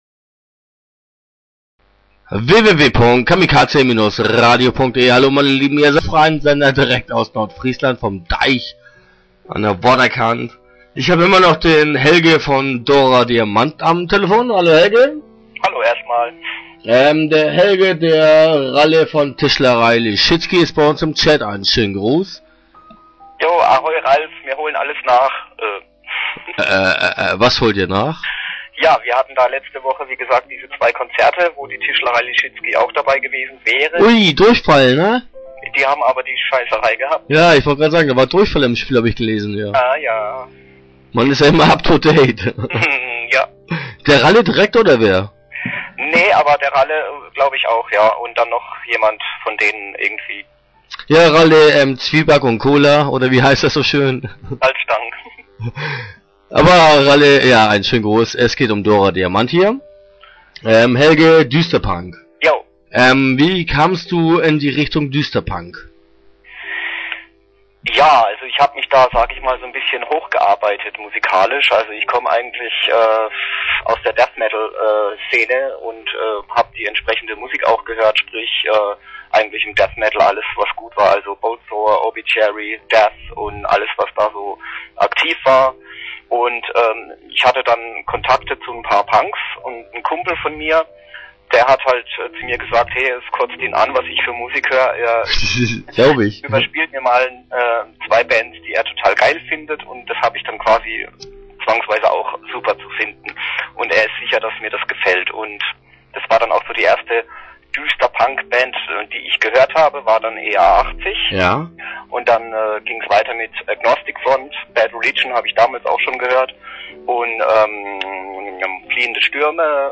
Interview Teil 1 (8:44)